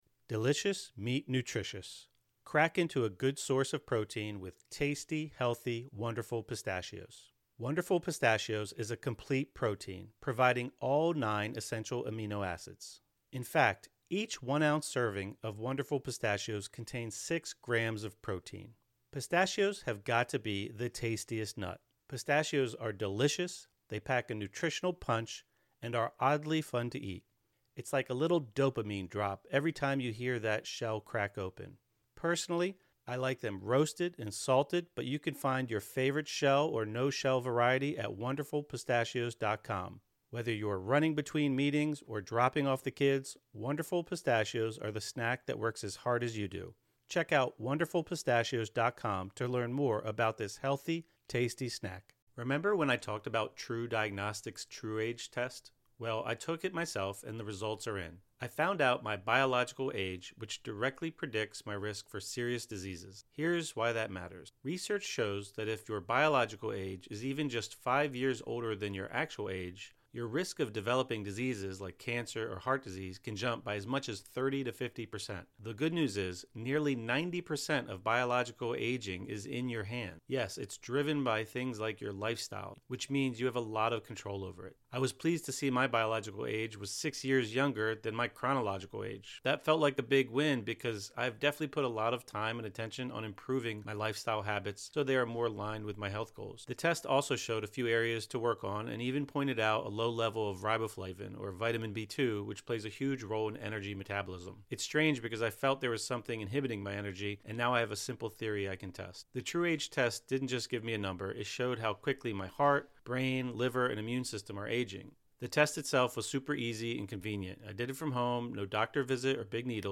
30 Minute Mindfulness Meditation | Let Go of Self-Judgement | Practicing a Beginner's Mind (; 08 Jun 2025) | Padverb